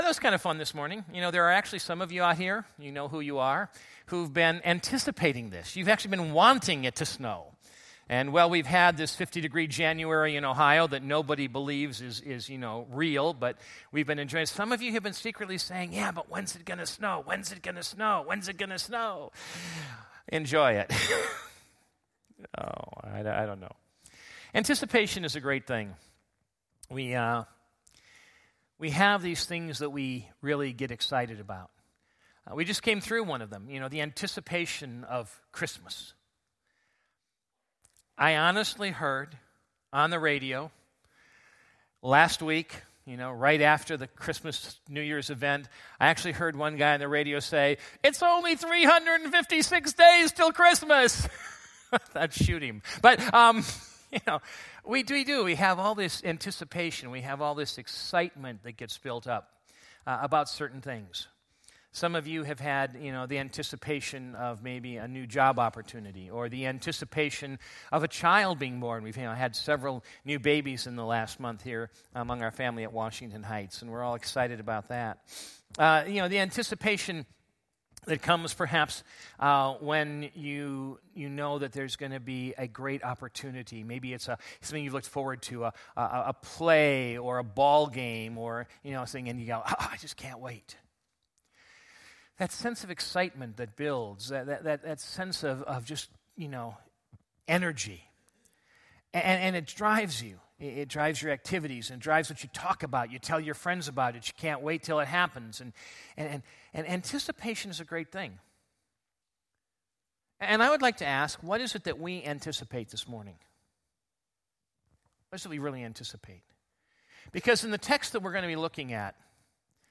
2016 Categories Sunday Morning Message Download Audio Download Notes Simeon & Anna Previous Back Next